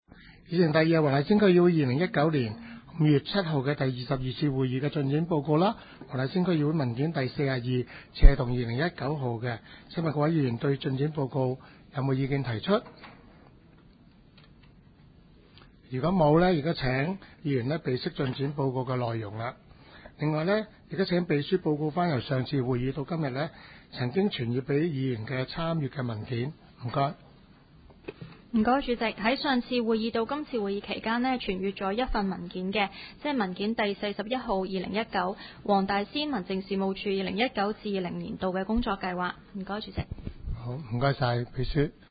区议会大会的录音记录
黄大仙区议会第二十三次会议
黄大仙区议会会议室